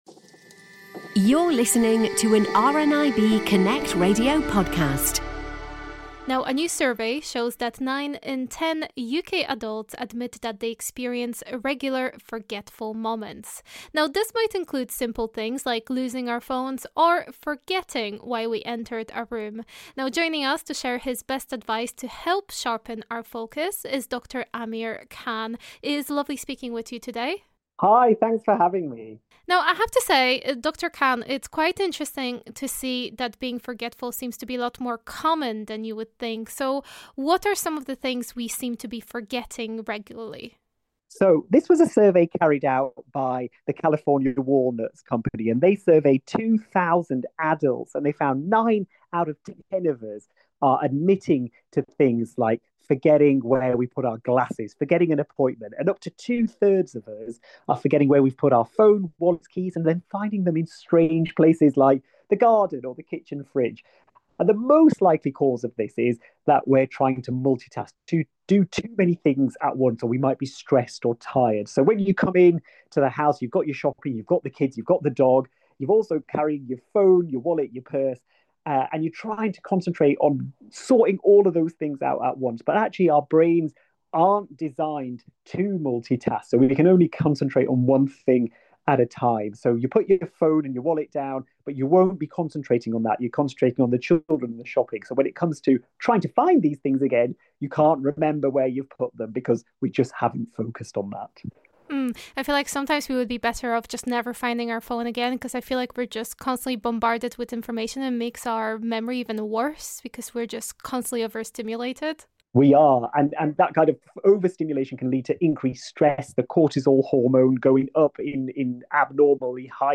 Joining us on the Happy Hour to share his best advice to help sharpen our focus is Dr Amir Khan.